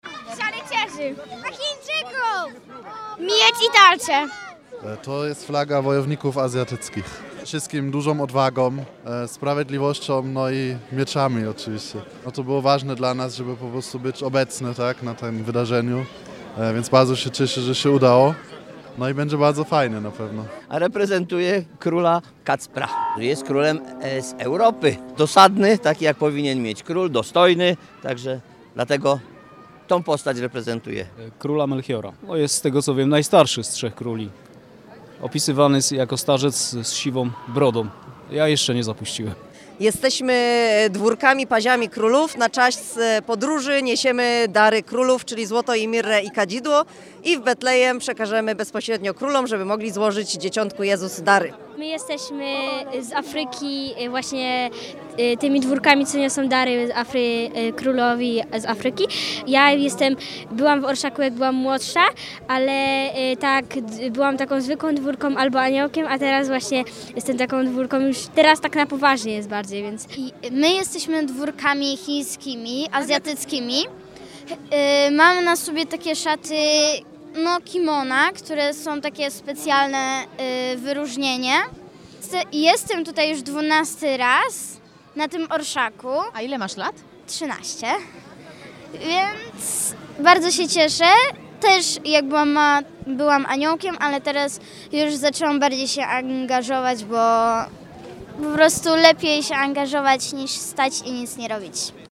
Po raz 13. przez Wrocław przeszedł Orszak Trzech Króli.
W orszaku spotkaliśmy Mędrców ze Wschodu, aniołów, dwórki, rycerzy oraz Chińczyków.